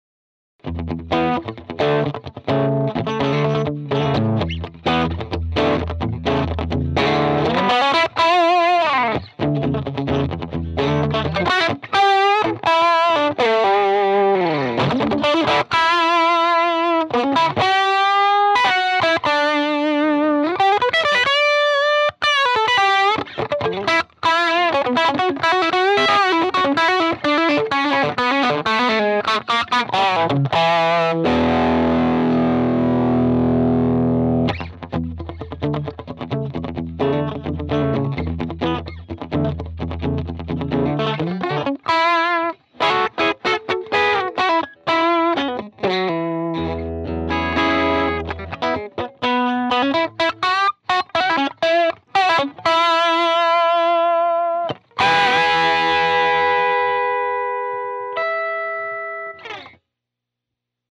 1) Clean boost.
I have done a short clip with a tele and the BD2.
The clip has no eq, no reverb, no compression or anything and is in mono.
This is not the hard rock setup but it has a clarity that I could get used to. The guitar is a '62RI tele using both pickups. I switch back and for between no boost for the chords and boost on for the single note lines. After 35 seconds it is back to no clean boost except for the last chord.
BD2Pedal_deluxe_cleanboost.mp3